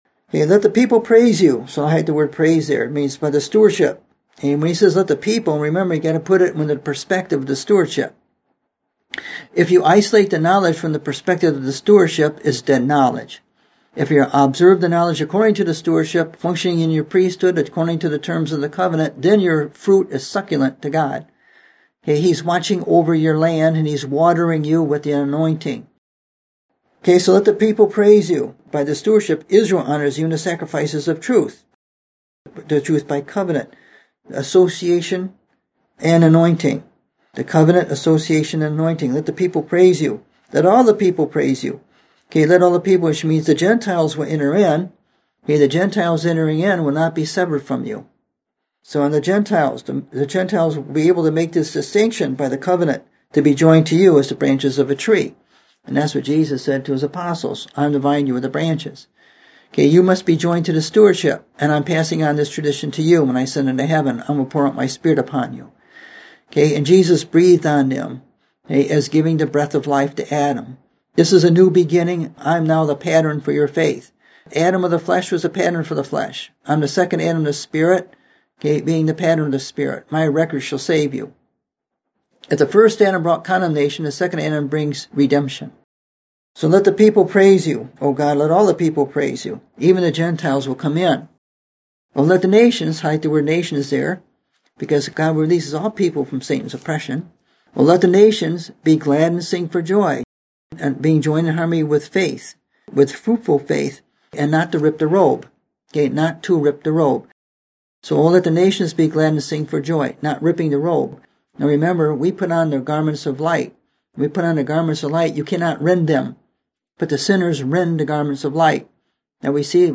Listen to the message I preached October 29, 2017: Tearing the Stewardship From Jesus